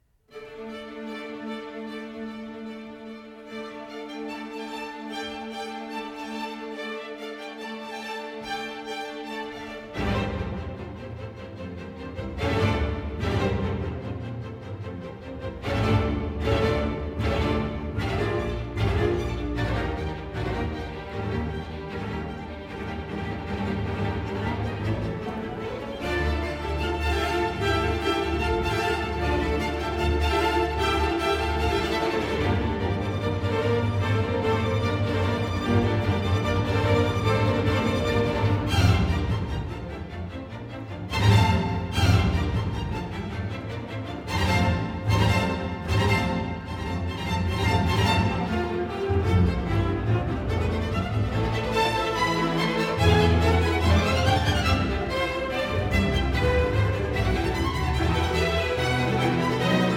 für Streichorchester d-Moll op. 70